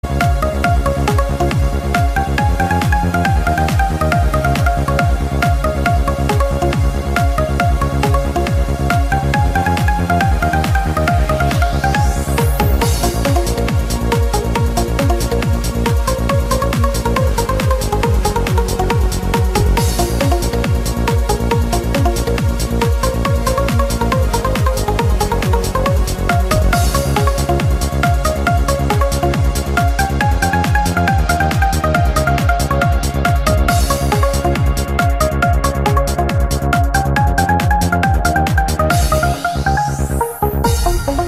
Kategorie Elektroniczne